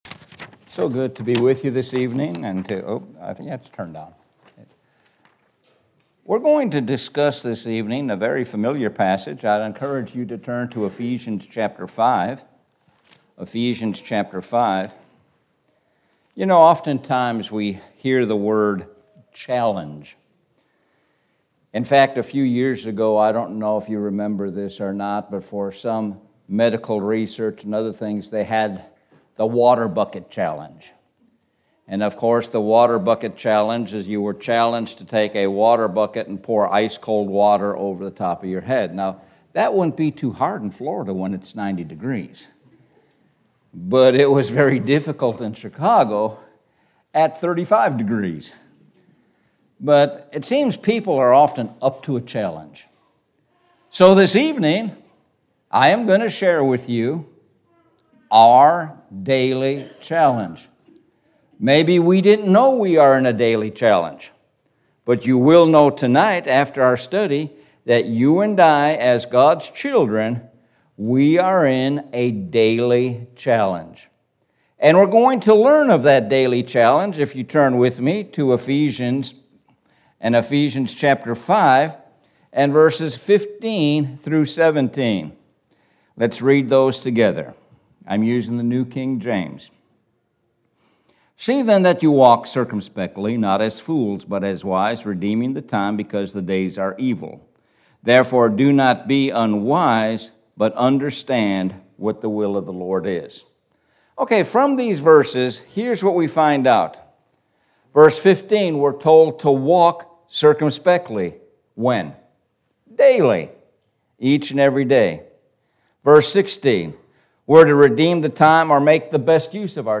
Sun PM Sermon – Our Daily Challenge